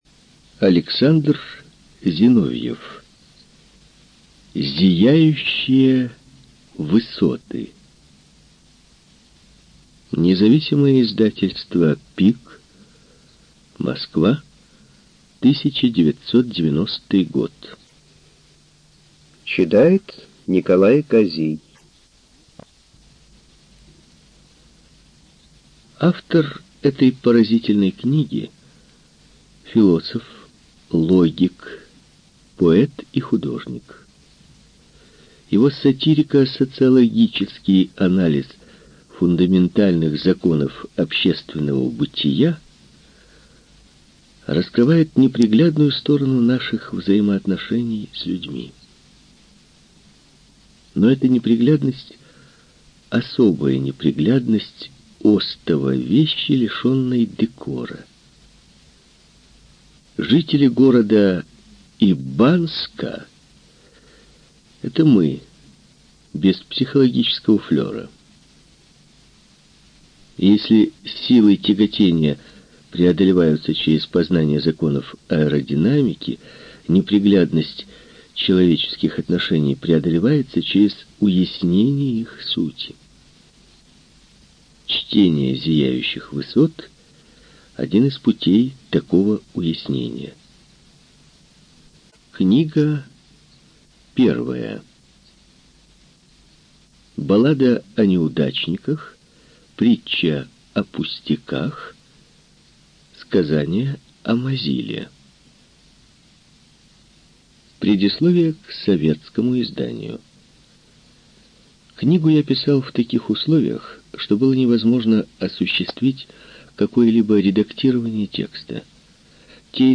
Студия звукозаписиРеспубликанский дом звукозаписи и печати УТОС